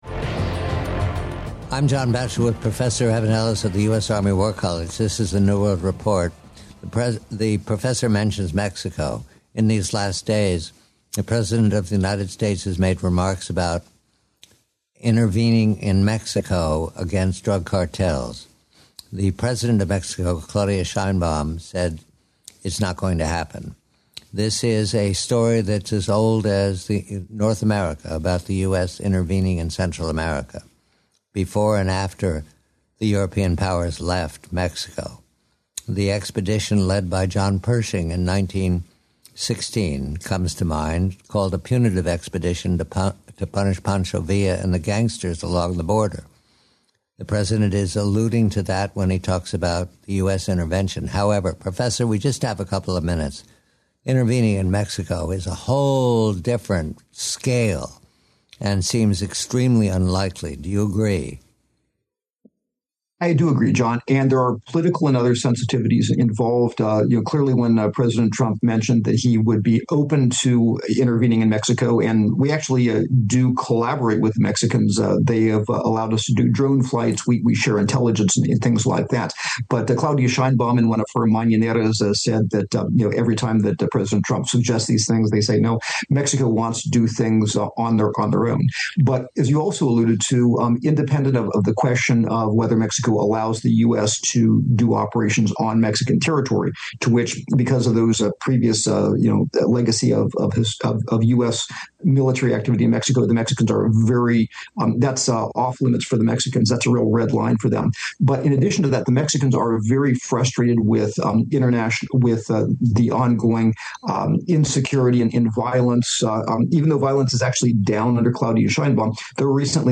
He emphasizes that narco-terror is a complex criminal economy troubling the region. The conversation also highlights rightward political movements and citizen frustration with insecurity and violence in Chile, Ecuador, and Peru. Guest: